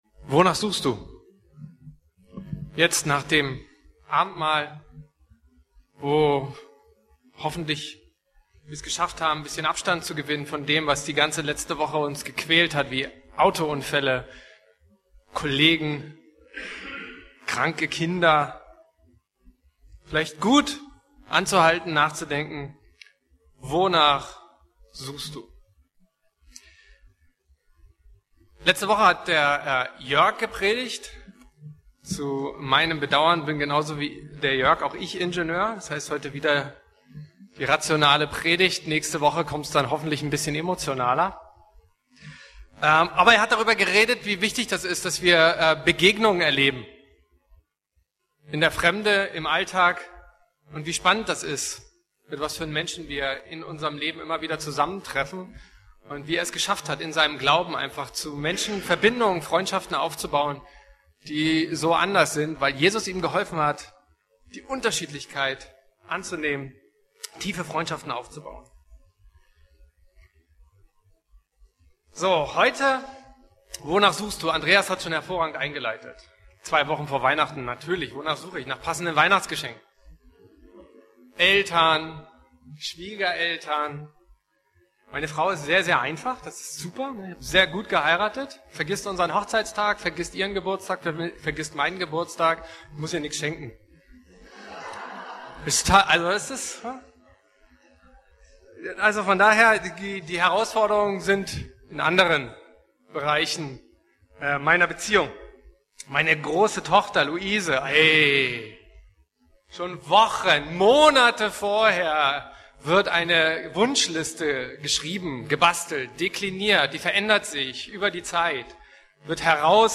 E-Mail Details Predigtserie: Advent Datum